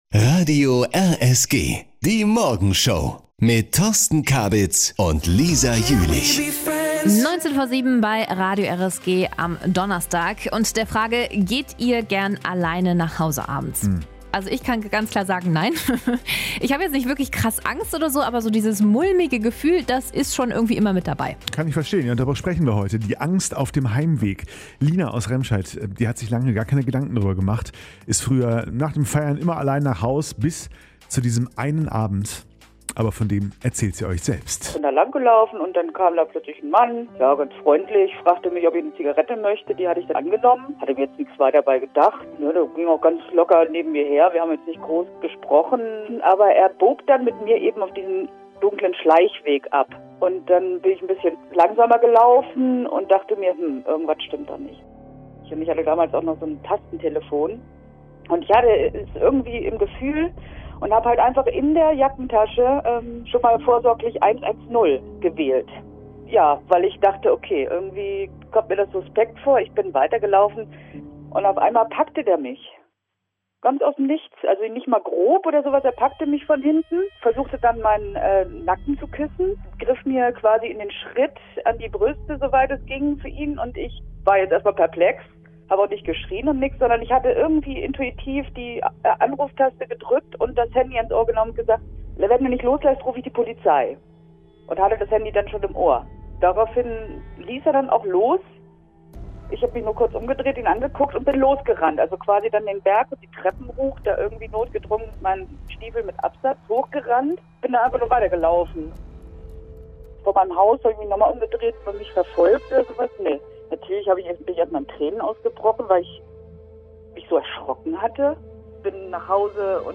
Außerdem haben wir in der Morgenshow das Heimwegtelefon und eine App vorgestellt, die für ein sicheres Gefühl auf dem Heimweg sorgen sollen.